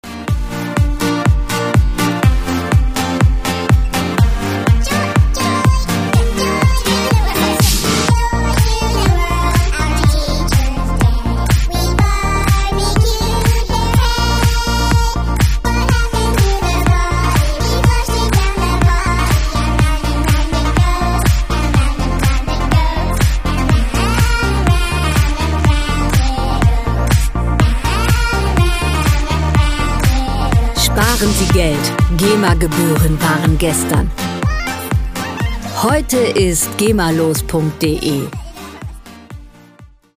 Musikstil: Pop
Tempo: 123 bpm
Tonart: B-Dur
Charakter: populär, herzig
Instrumentierung: Kindergesang, Synthesizer